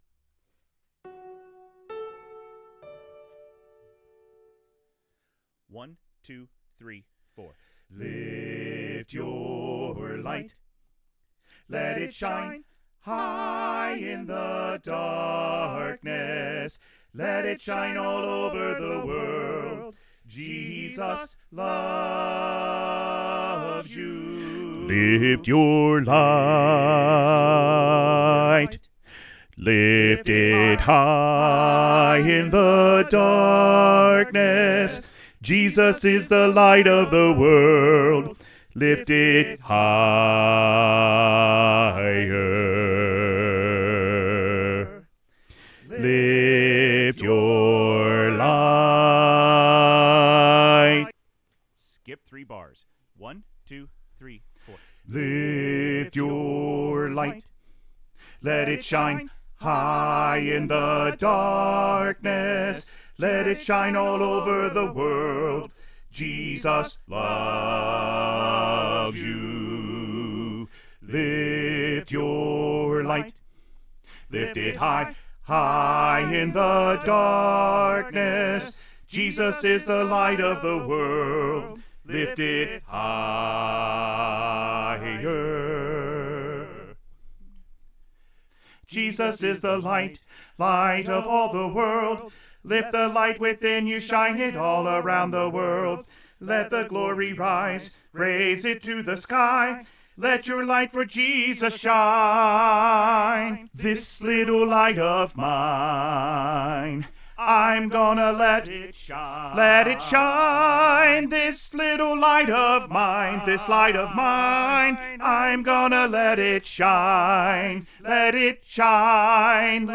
Choir Music Learning Recordings
Lift Your Light - Bass Emphasized All 4 Parts with The Bass Part Emphasized